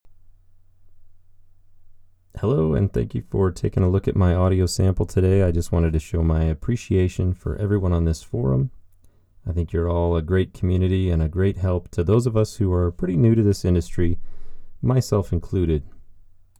Special Interest Groups Audiobook Production
I’ve attached a raw WAV sample, and wonder if you pro’s wouldn’t mind taking a look at it, and giving me any pointers/tips?
There is a stunning amount of sub-sonic noise.
As for my studio, I’ve got a desk set up in a closet under my stairs.
As shown below, there is a strong peak at about 11 Hz.
I’m more concerned with the announcing in a rain barrel sound. The studio worked in the sense that you have almost no background sound or noise, But it does sound like you’re announcing in a small elevator.
The existing bare walls are also giving you a false announcing boom sound.